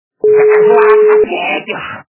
» Звуки » Люди фразы » Голос - За козла ответишь
При прослушивании Голос - За козла ответишь качество понижено и присутствуют гудки.